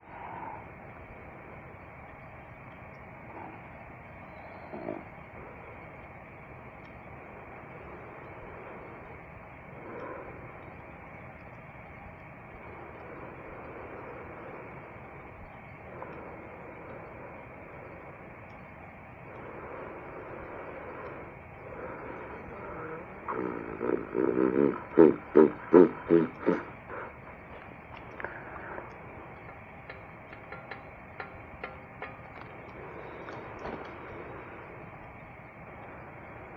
小生、カセットレコーダーにて録音した、ブ～ン音を再生しながら（右参考）。